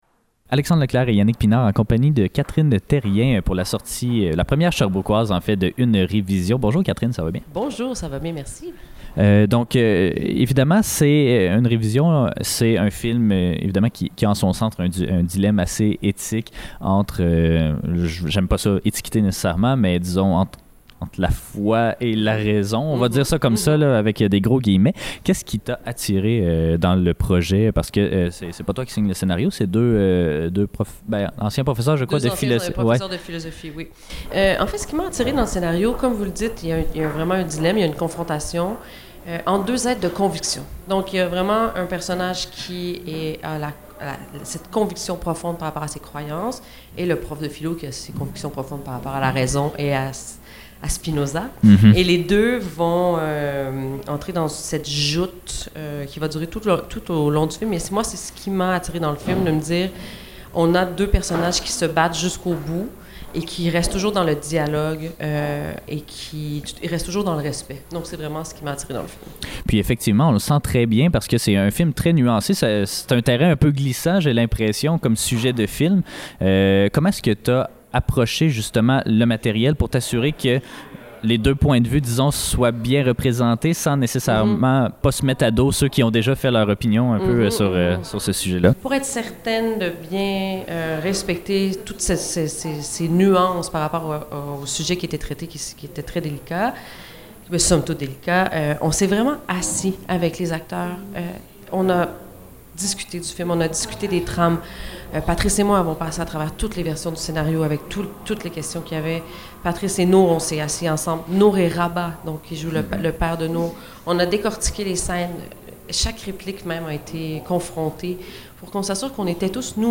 Les entrevues de CFAK Ciné-histoire - Entrevue